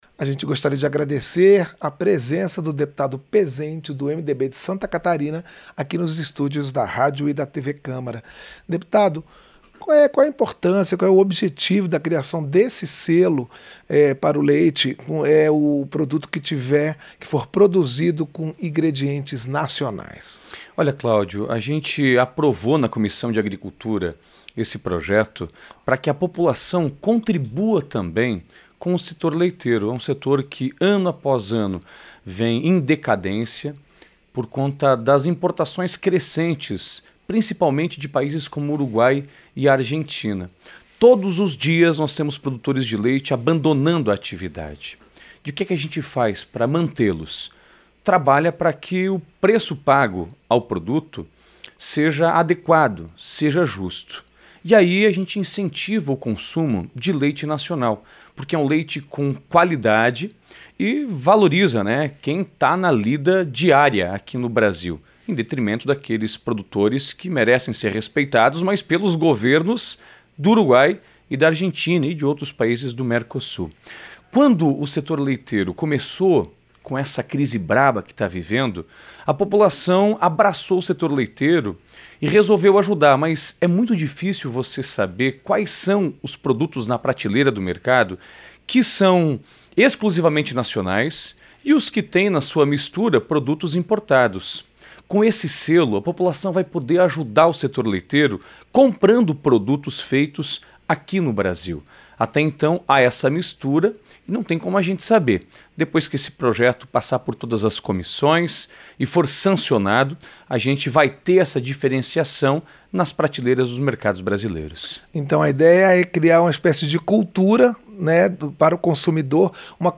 Entrevista - Dep. Pezenti (MDB-SC)